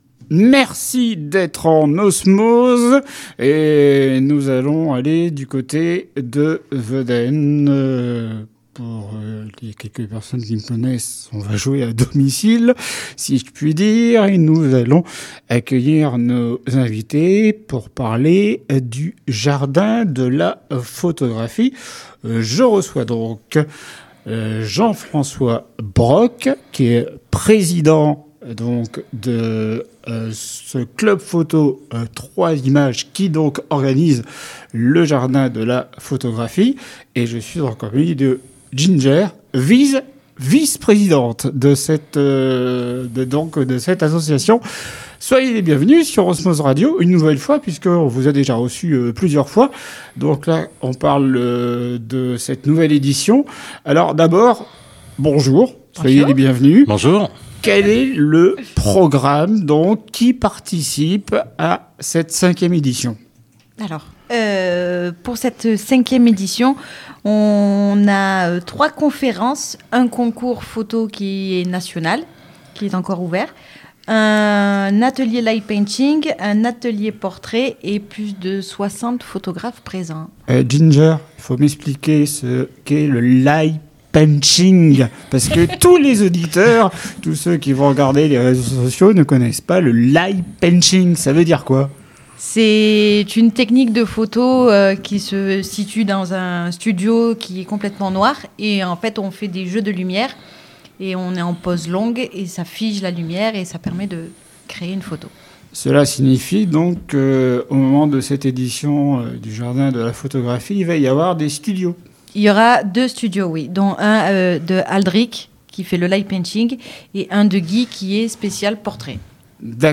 Culture/Loisirs Interviews courtes